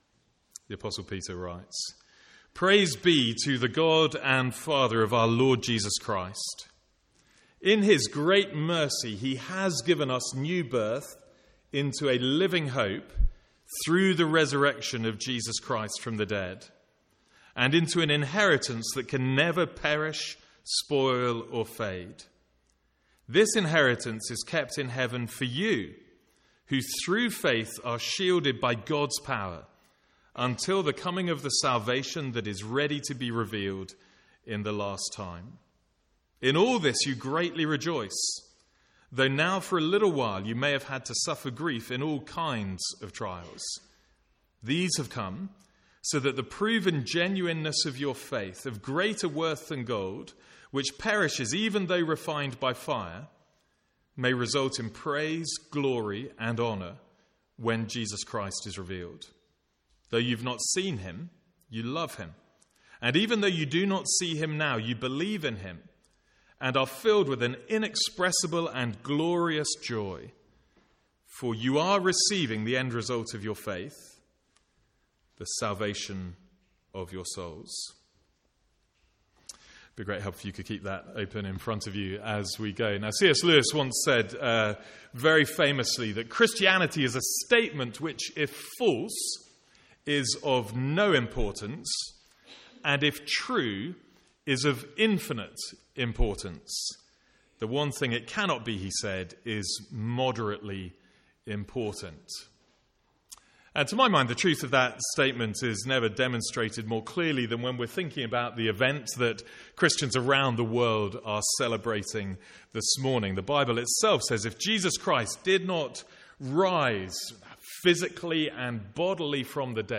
From the morning service on Easter Sunday 2016.